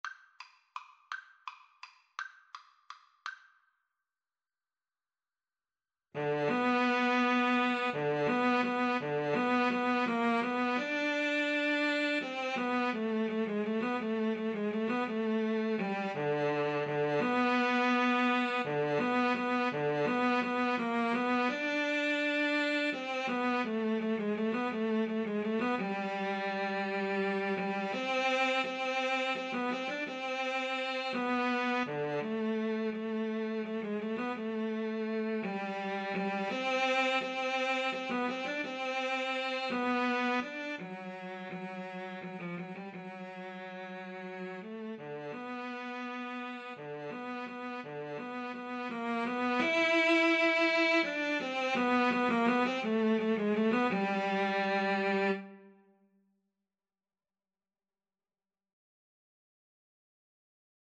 Tempo di valse =168
Classical (View more Classical Cello Duet Music)